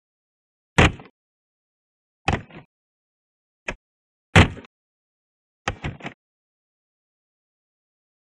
Prop Plane; Door Open and Close; Commanche Door Open And Closed.